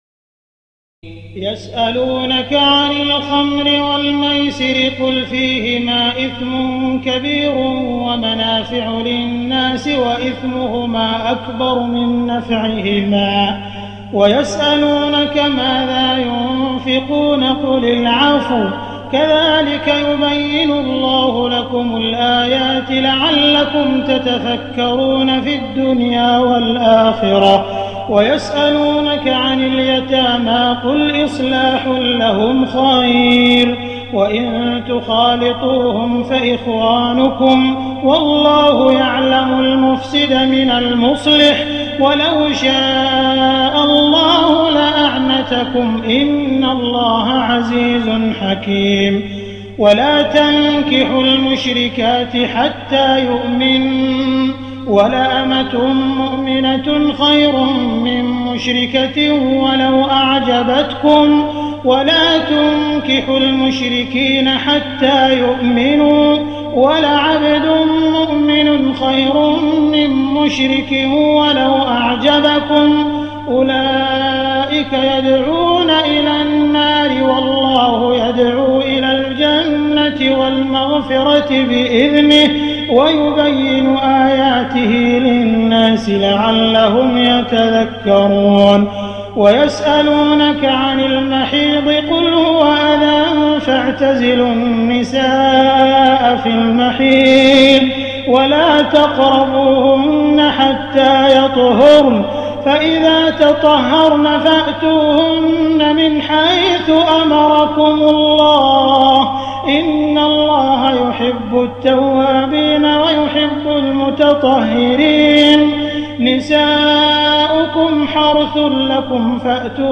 تراويح الليلة الثانية رمضان 1419هـ من سورة البقرة (219-271) Taraweeh 2st night Ramadan 1419H from Surah Al-Baqara > تراويح الحرم المكي عام 1419 🕋 > التراويح - تلاوات الحرمين